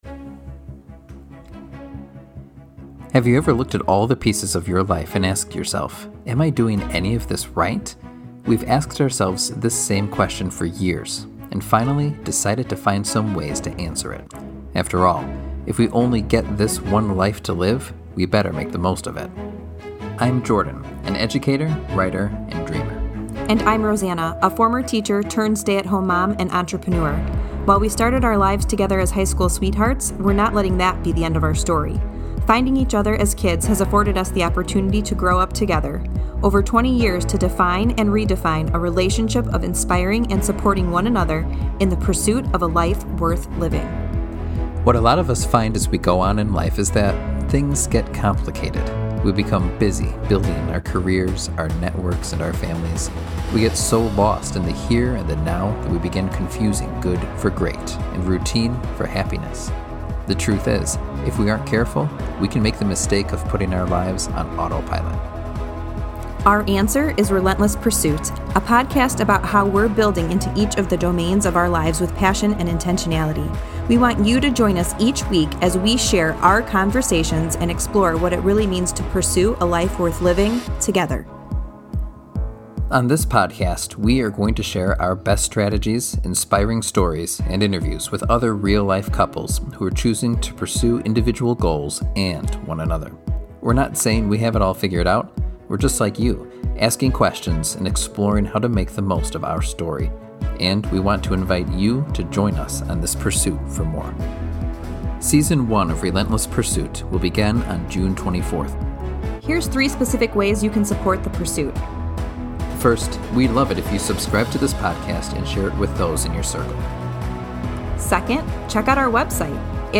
The TRAILER is now LIVE!